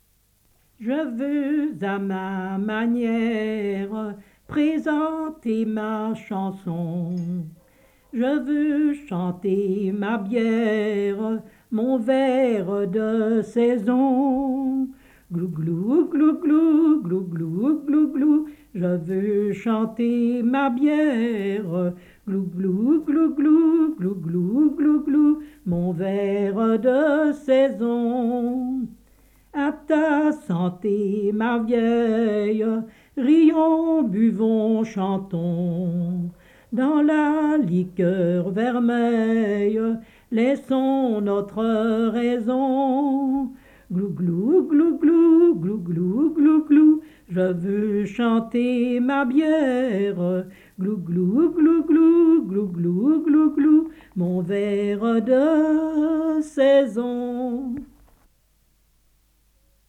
Type : chanson narrative ou de divertissement | Date : Juillet-août-septembre 1972